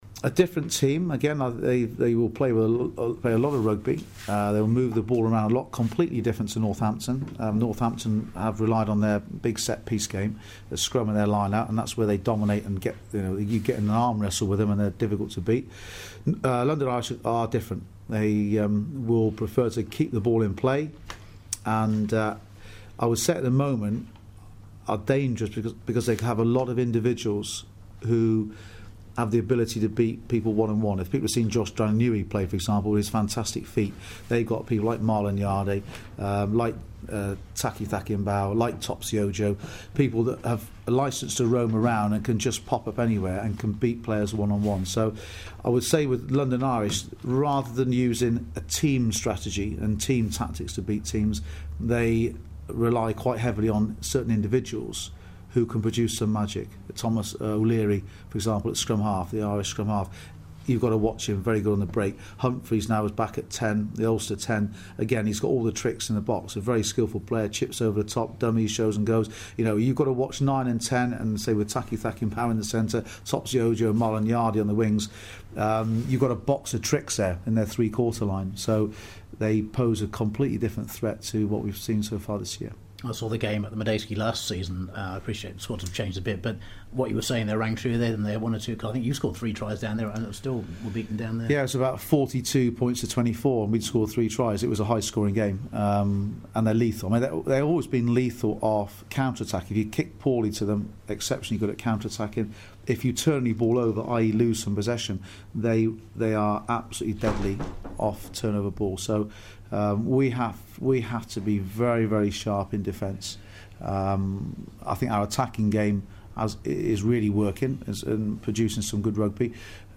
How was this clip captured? talks to BBC Hereford and Worcester ahead of the home clash with London Irish.